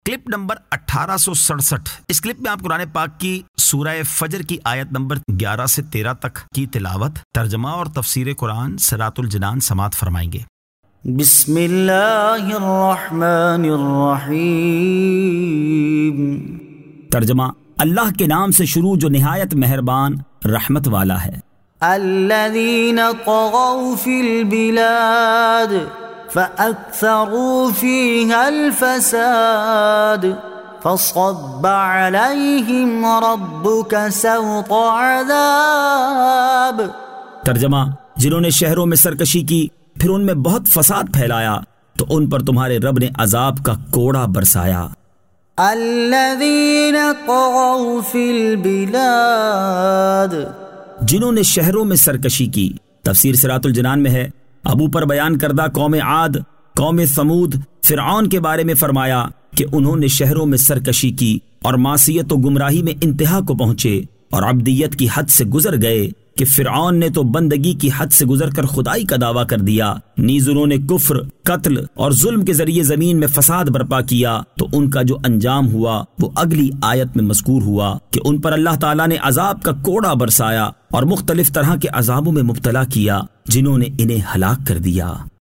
Surah Al-Fajr 11 To 13 Tilawat , Tarjama , Tafseer